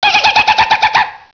gennan-yell.wav